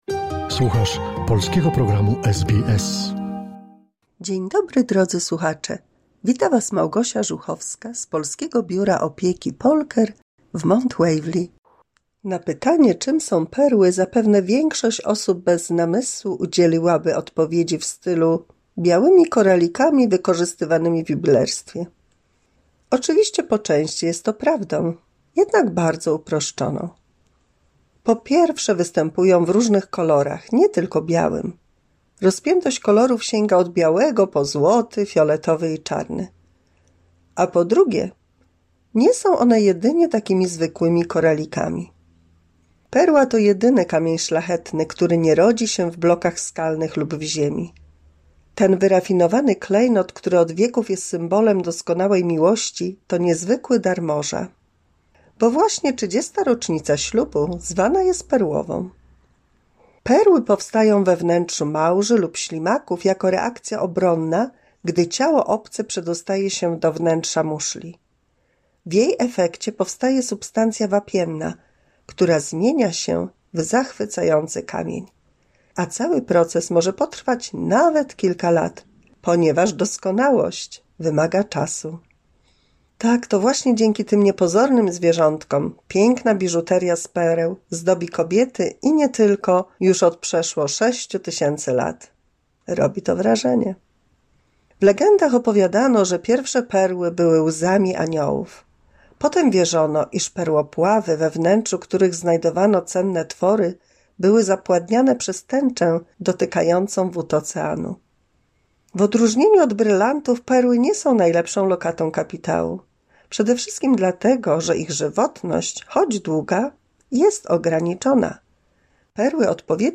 W 217 mini słuchowisku dla polskich seniorów usłyszymy ciekawostki o perłach i pierwszą część materiału o Kazimierzu Jagielończyku królu Polski , który był dobrym tatą.